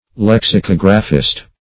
lexicographist - definition of lexicographist - synonyms, pronunciation, spelling from Free Dictionary
Search Result for " lexicographist" : The Collaborative International Dictionary of English v.0.48: Lexicographist \Lex`i*cog"ra*phist\ (l[e^]ks`[i^]*k[o^]g"r[.a]*f[i^]st), n. A lexicographer.